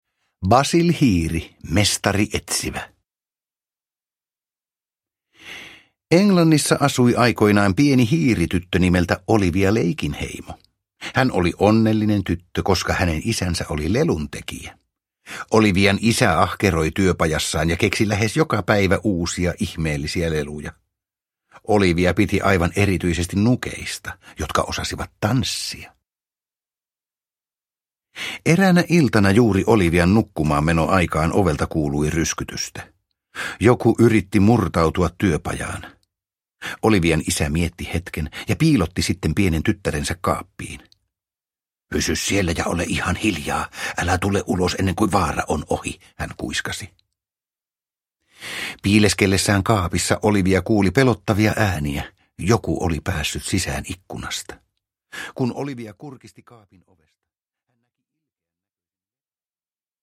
Basil Hiiri, mestarietsivä – Ljudbok – Laddas ner
Produkttyp: Digitala böcker